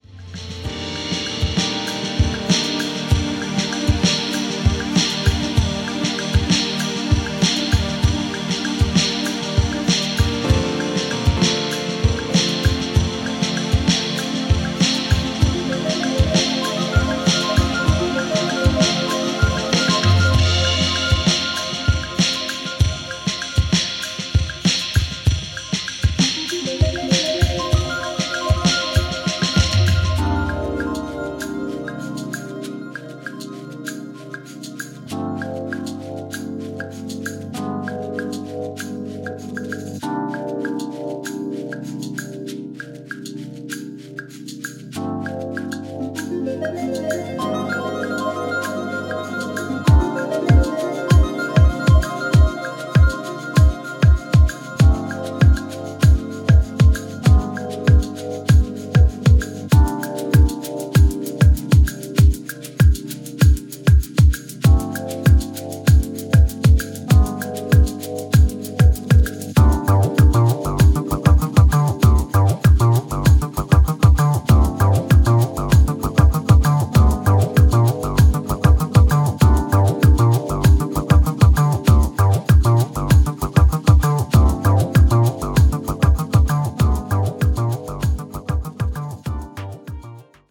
ジャンル(スタイル) HOUSE / BALEARIC